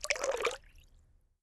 water-splash-03
bath bathroom bubble burp click drain dribble drop sound effect free sound royalty free Nature